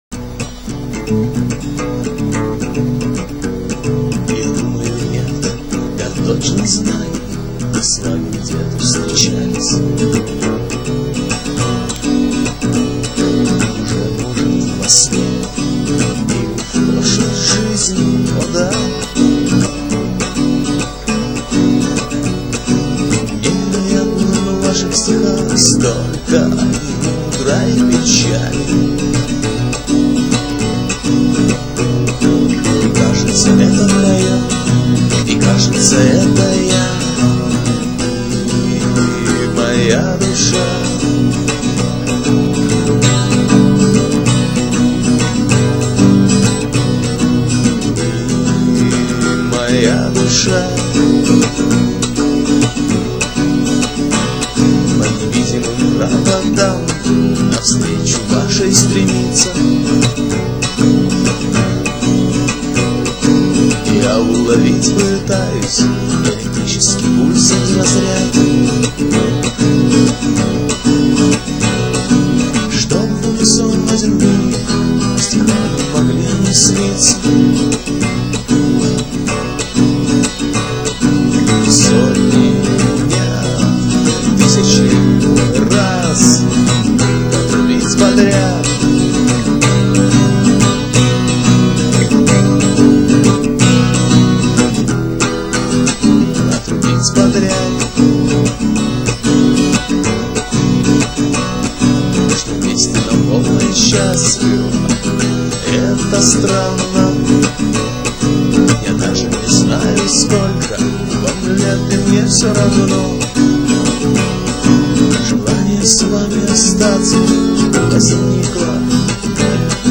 Гитара и вокал